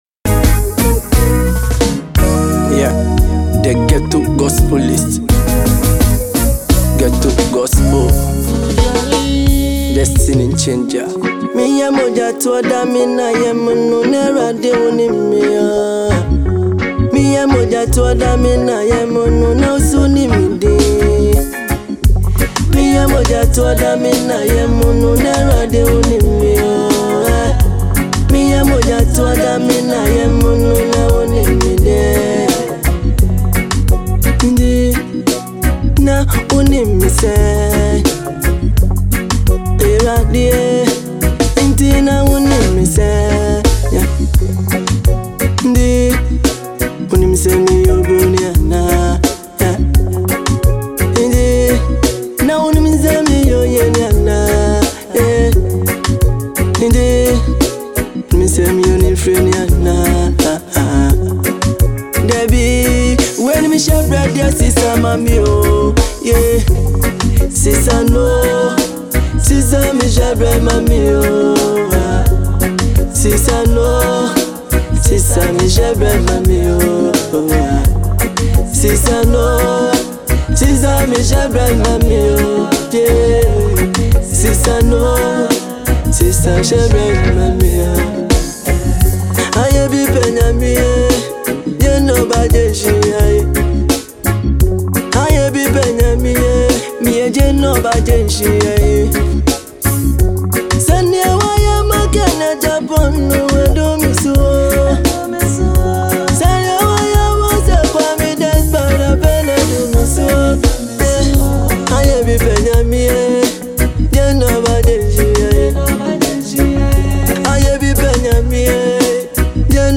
Christian music